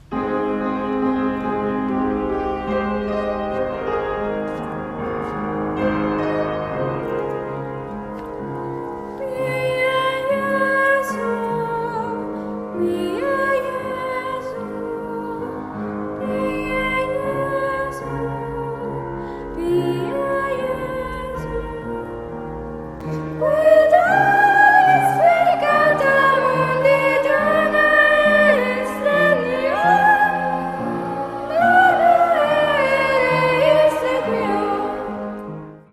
Freifach Chorsingen - Singen ist Trend
Unser Chor singt seit einem Jahr an verschiedenen Anlässen.